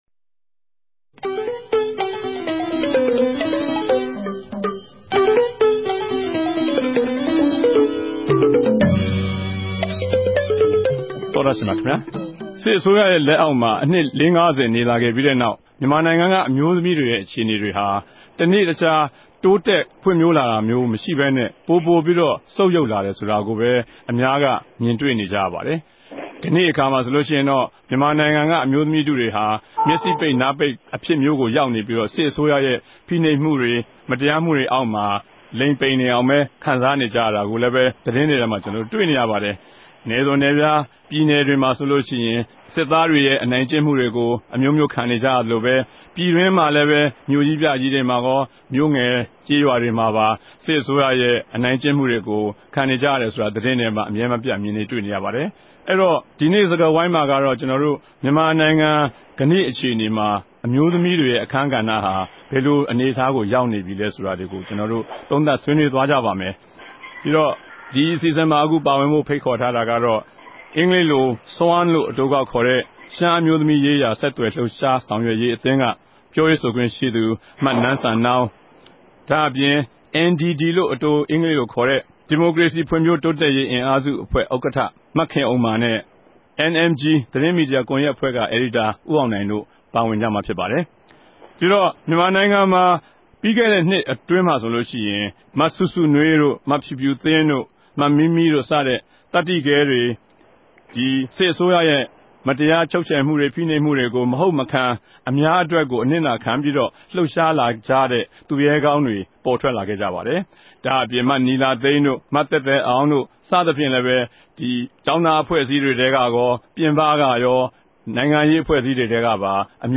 တနဂဿေိံြ ဆြေးေိံြးပြဲ စကားဝိုင်း။